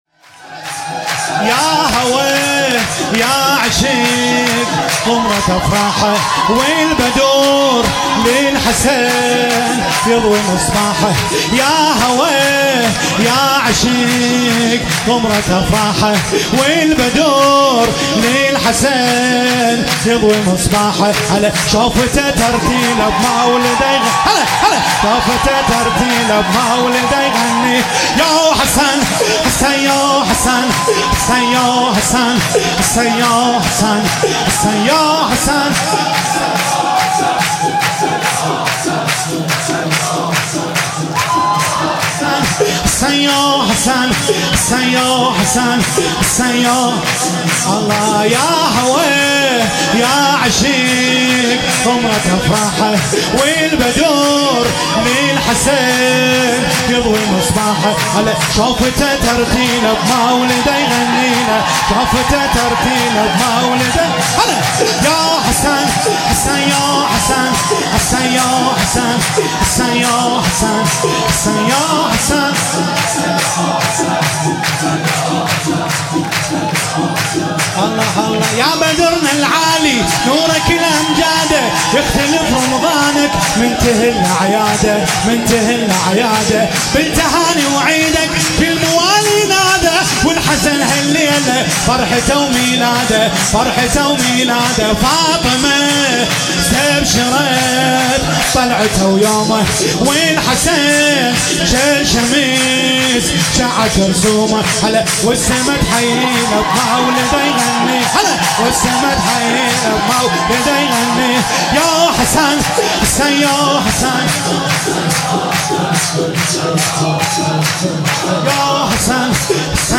ولادت امام حسن (ع)
شور